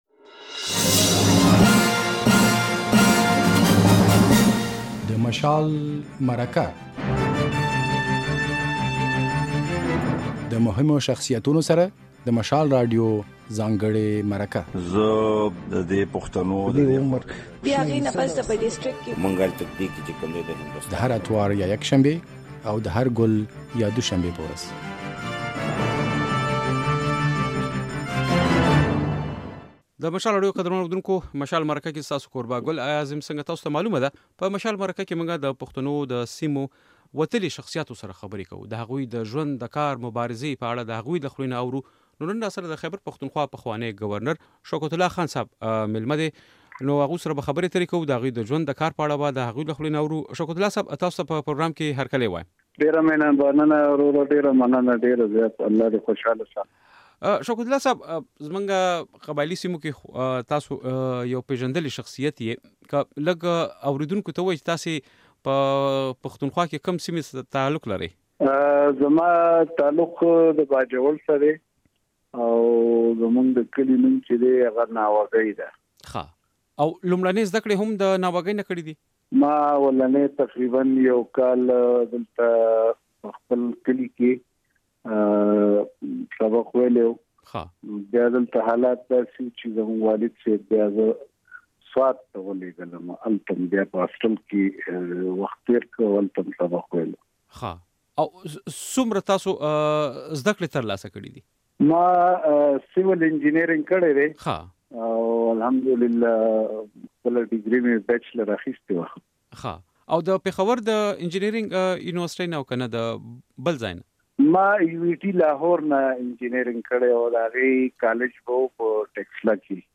د مشال راډیو په اوونيزه خپرونه "د مشال مرکه" کې دا ځل د خيبر پښتونخوا پخوانی ګورنر انجينير شوکت الله خان مېلمه دي.